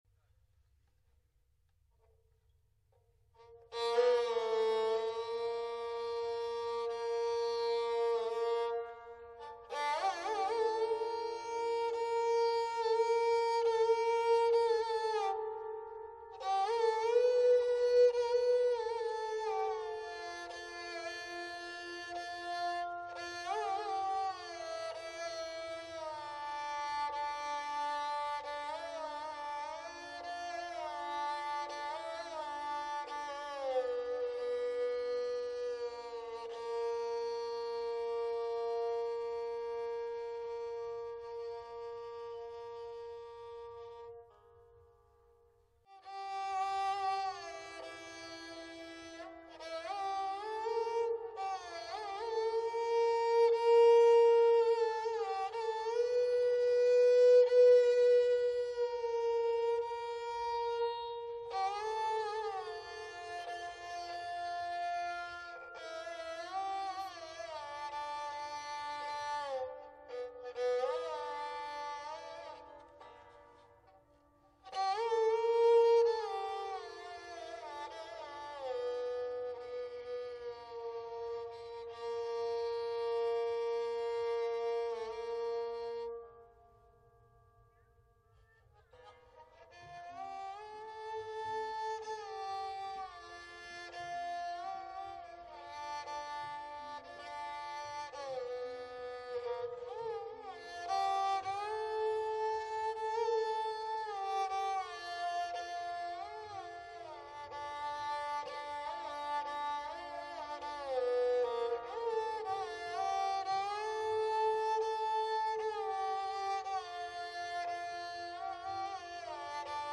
Thaat: Marwaha, Jatti: Samporan, Rishab is komal & Madhayam teevra, rest all surs are shudh.
Ni(mander) Re(k) Ga Pa, Ma(t) Ga, Ma(t) Dha Sa
Pakar:  Pa Dha Ga, Ma(t) Dha, Ma(t) Ga, Re(k) Ga, Ma(t) Ga, Re(k) Sa
Dilruba :
bairari_dilruba.mp3